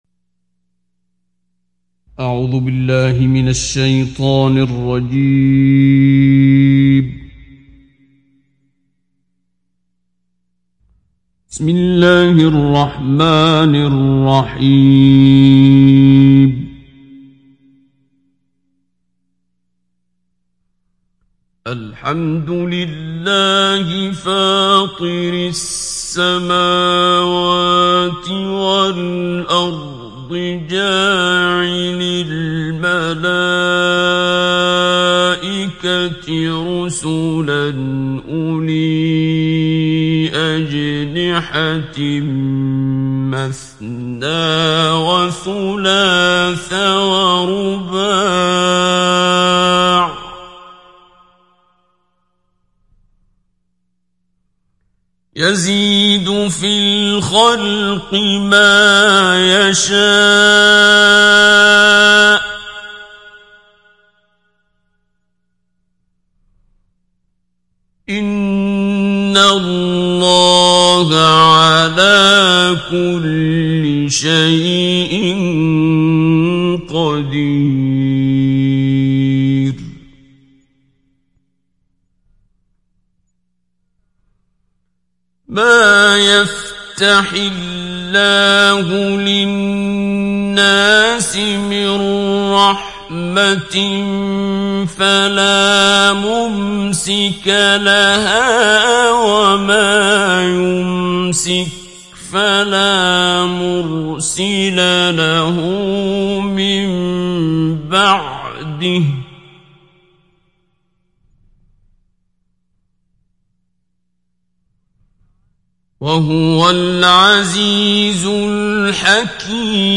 সূরা ফাতের ডাউনলোড mp3 Abdul Basit Abd Alsamad Mujawwad উপন্যাস Hafs থেকে Asim, ডাউনলোড করুন এবং কুরআন শুনুন mp3 সম্পূর্ণ সরাসরি লিঙ্ক
ডাউনলোড সূরা ফাতের Abdul Basit Abd Alsamad Mujawwad